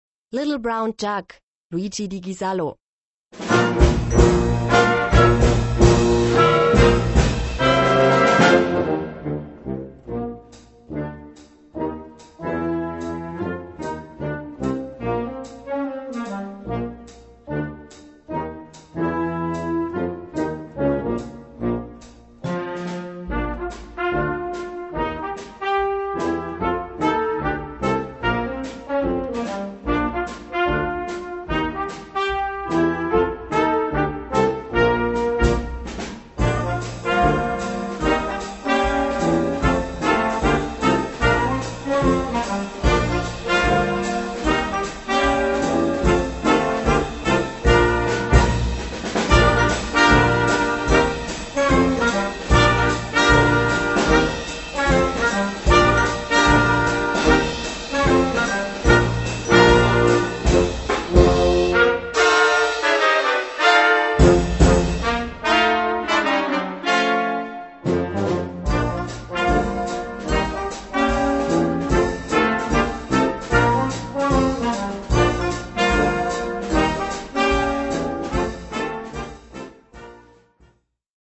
Gattung: Swing
Besetzung: Blasorchester